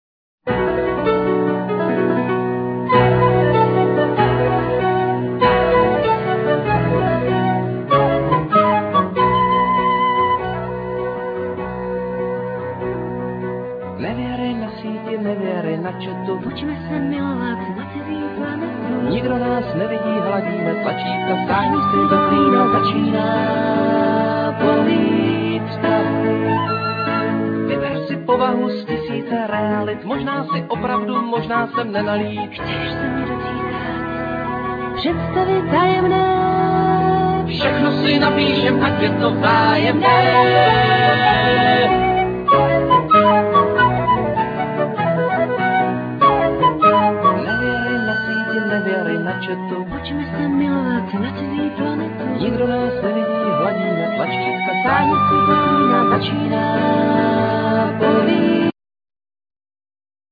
Piano,Keyboards,Guitar,Trumpet,Vocal,etc
Cello,Saxophone,Vocal,etc
Flute,Piano,Keyboards,etc